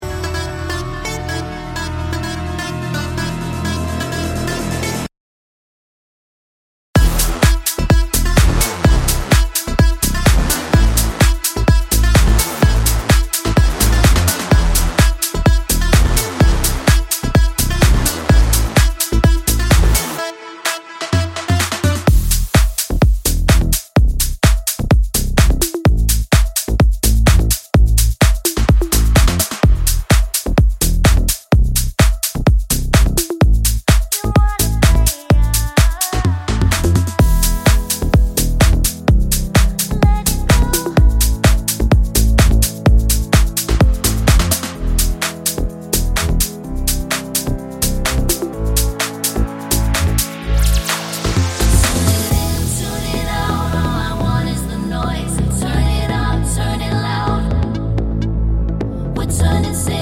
no Backing Vocals Pop (2020s) 2:43 Buy £1.50